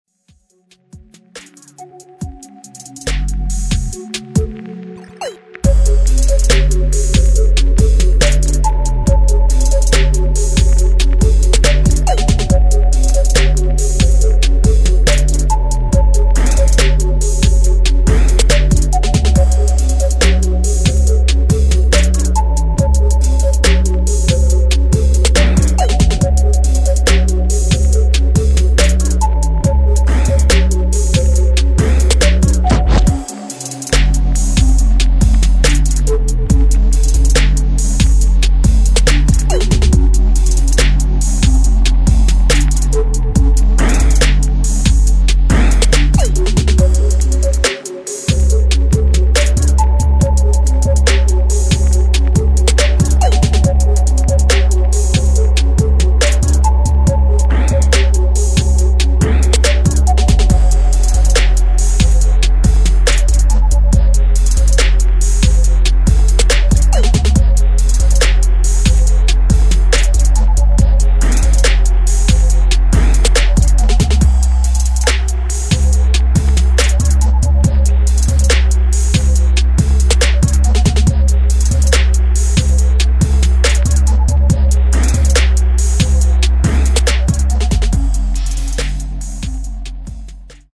[ DUBSTEP / DRUM'N'BASS ]